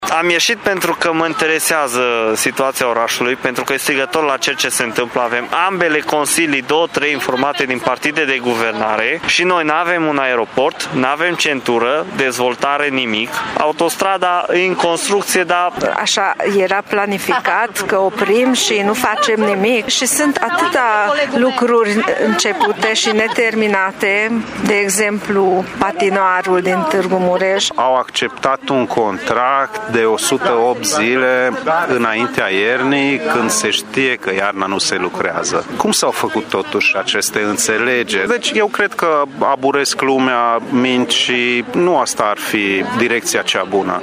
Nici o investiție în infrastructură nu este, de altfel, finalizată în Tîrgu-Mureș, iar pentru asta autoritățile trebuie să dea explicații, spun protestatarii: